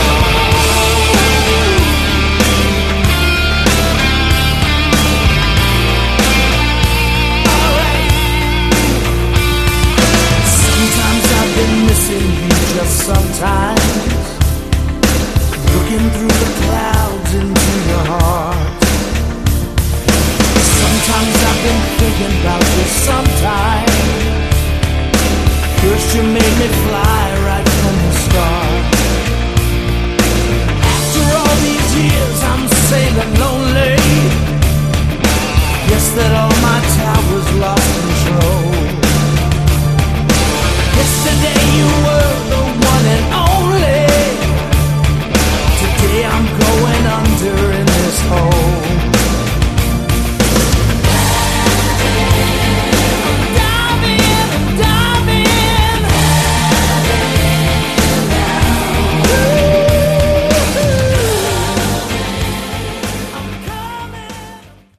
Category: AOR
vocals